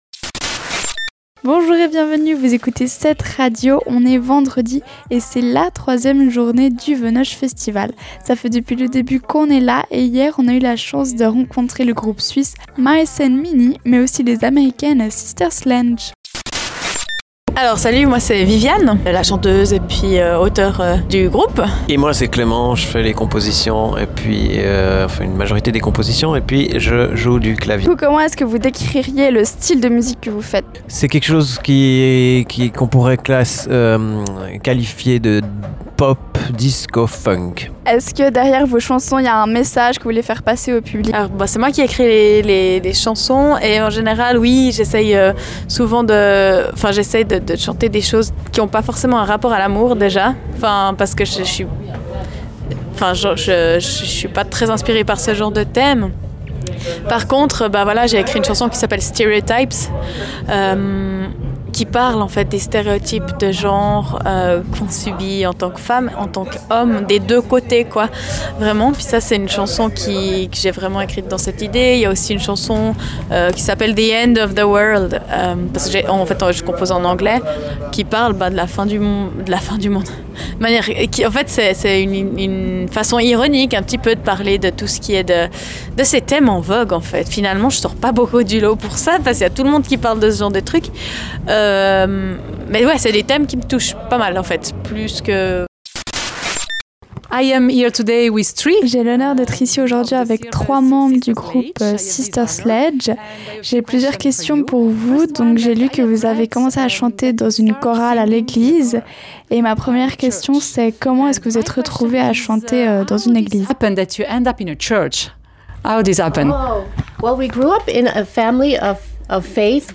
Pour ce troisième jour au Venoge Festival on a eut la chance de rencontrer le groupe Mice And Minie mais aussi Sister Sledge.